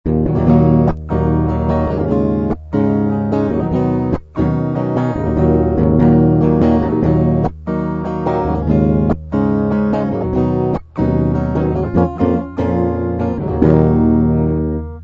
Проигрыш: